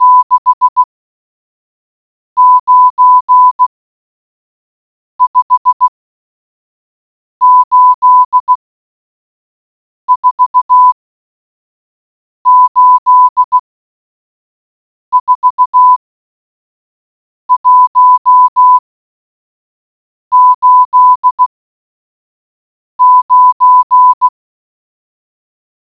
Download Morse audio
morseAudio.wav